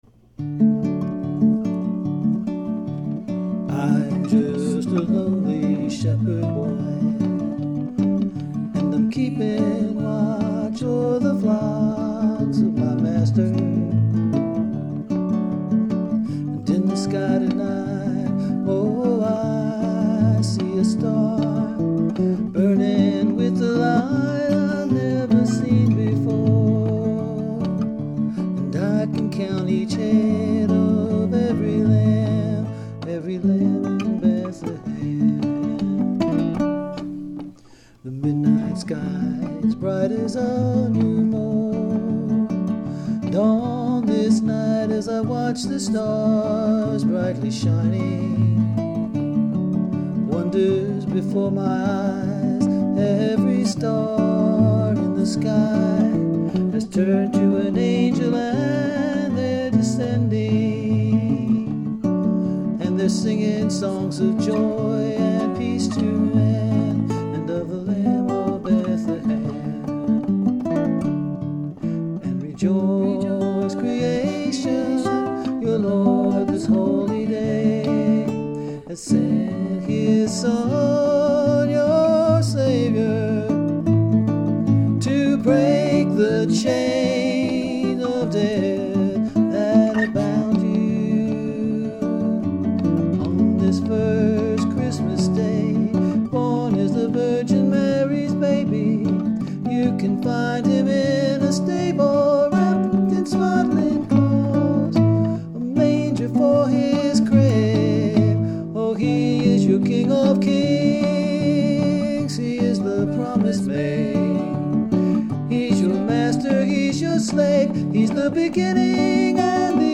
It is a Christmas song that tells the birth of Jesus from the point of the shepherd boy watching his flocks.